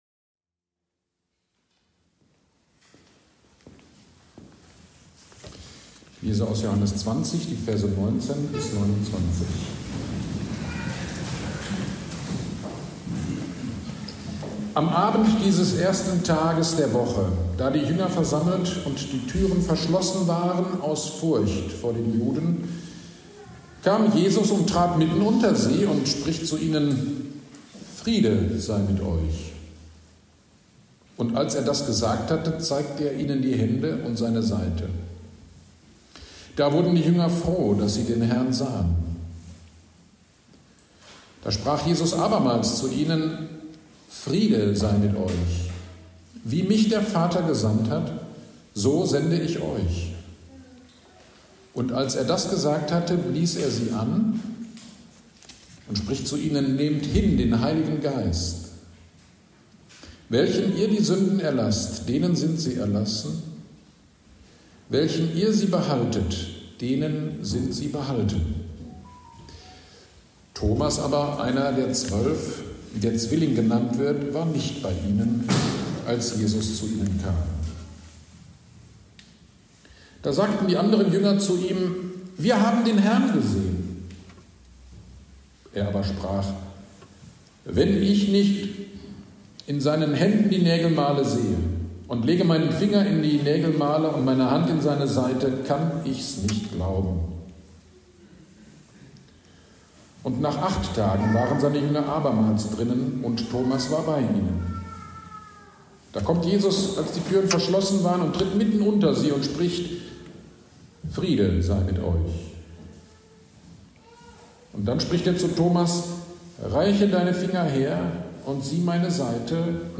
Fest-GD am 27.04.2025 - Predigt zu 1. Petrus 1, 3-9 - Kirchgemeinde Pölzig